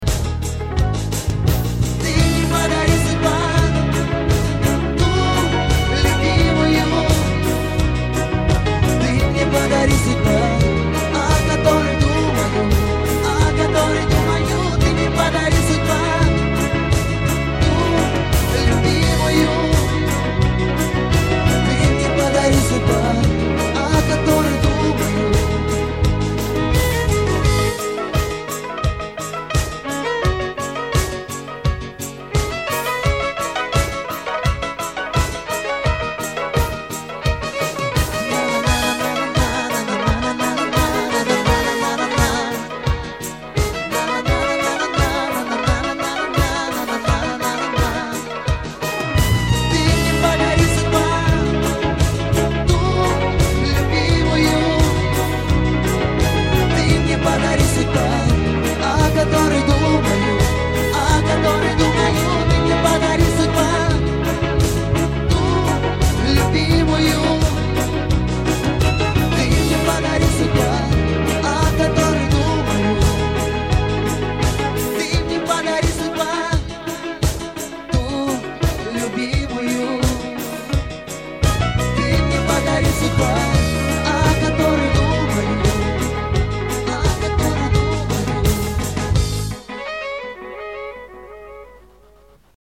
• Качество: 320, Stereo
русский шансон